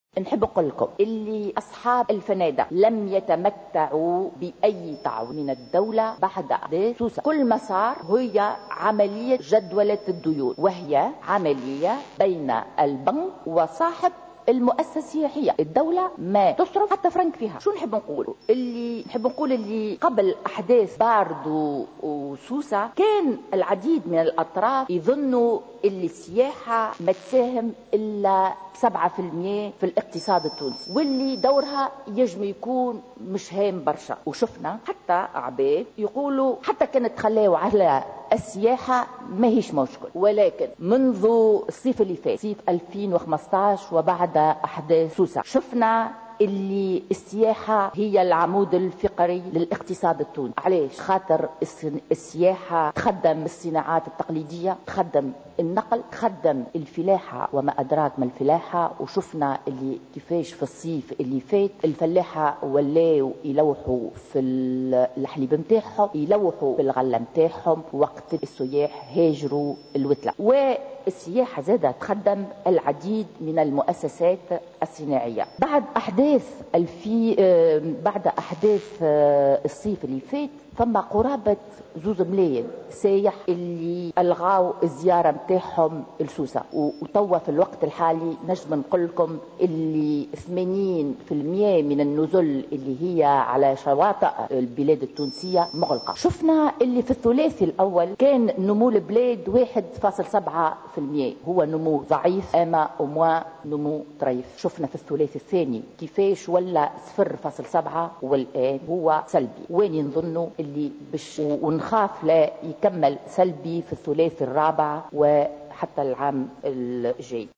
أكدت النائبة عن نداء تونس زهرة ادريس في مداخلة لها اليوم في الجلسة العامة المخصصة لمناقشة ميزانية وزارة السياحة أكدت أن قطاع السياحة بعد أحداث باردو و سوسة تدهور بشكل كبيّر بعد أن قام اكثر من مليـُوني سائح بإلغاء حجوزاتهم في مختلف نزل جهة سوسة و 80% من النزل الموجودة على الشواطئ التونسية أغلقت أبوابها.